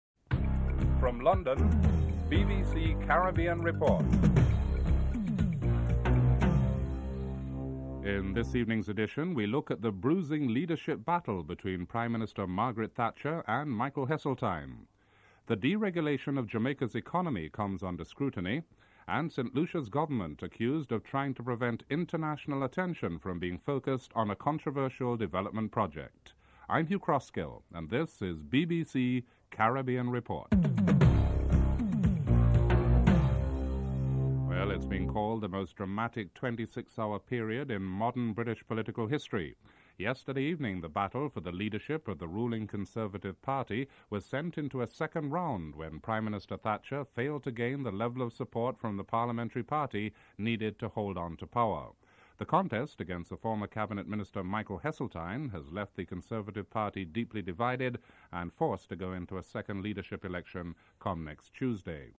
1. Headlines (00:00-00:34)